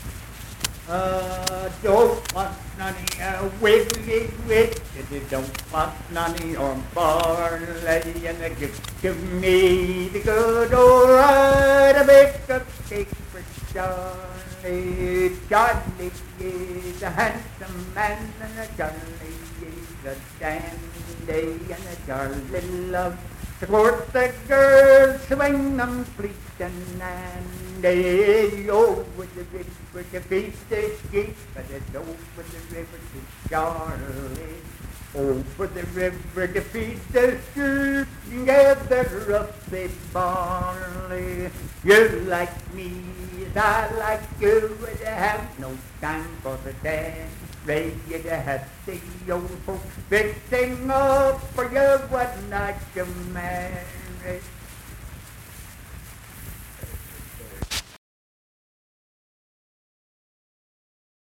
Unaccompanied vocal music performance
Verse-refrain 4(4).
Dance, Game, and Party Songs
Voice (sung)